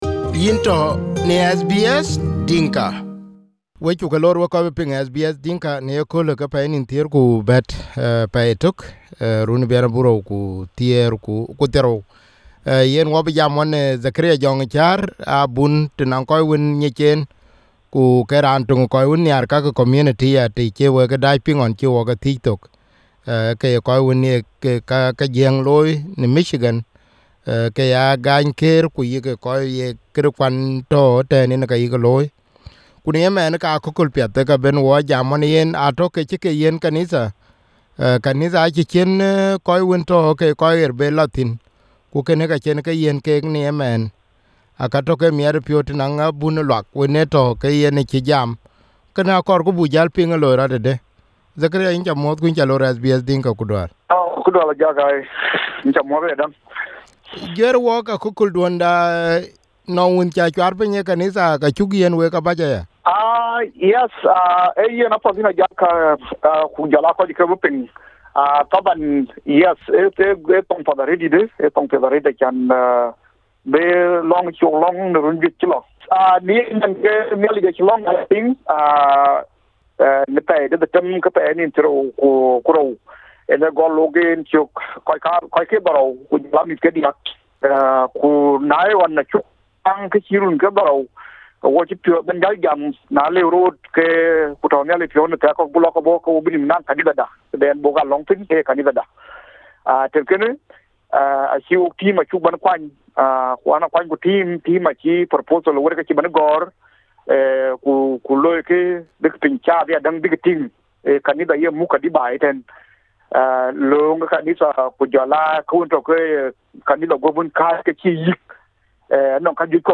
was interviewed on SBS Dinka radio.